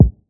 kits/OZ/Kicks/K_Slow.wav at main
K_Slow.wav